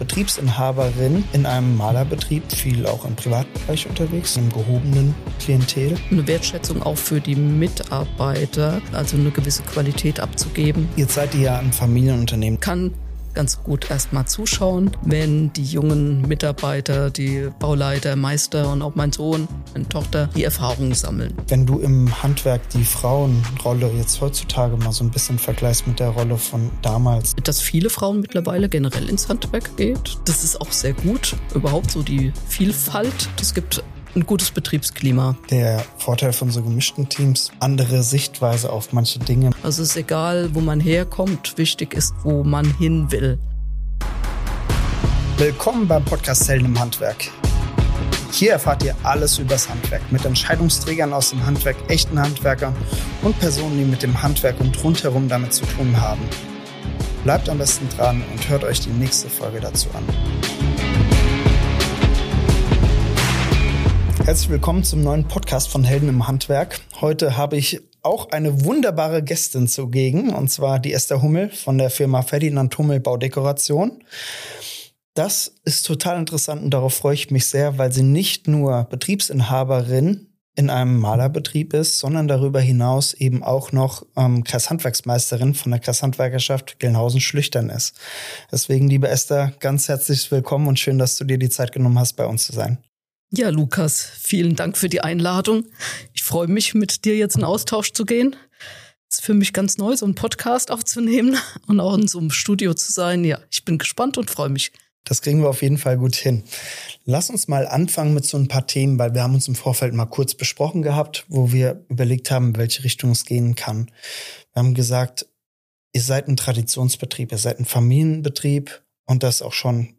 In dieser Podcast-Folge sprechen wir mit einer erfahrenen Unternehmerin aus dem Bauhandwerk über die Herausforderungen und Chancen, die ein Familienbetrieb mit sich bringt.